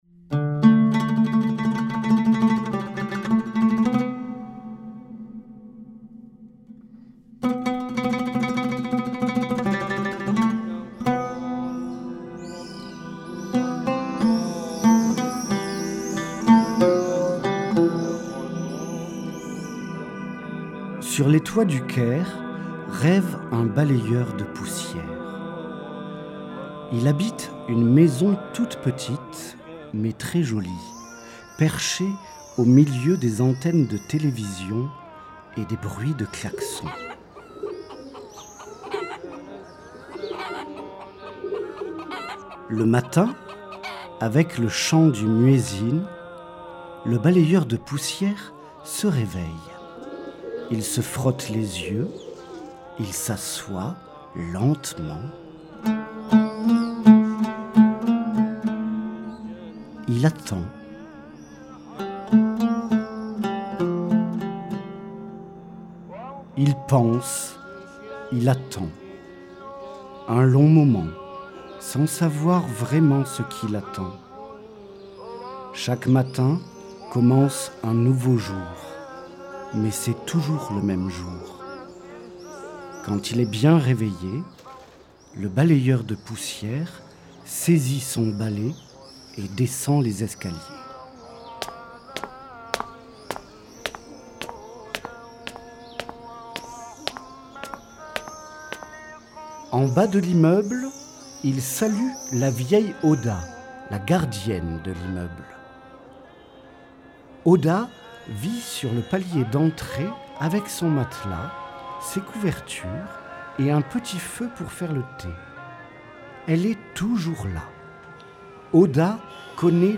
Cette version sonore contient la lecture en français (8’30), la chanson du balayeur (2’30), puis la lecture en arabe (8’30).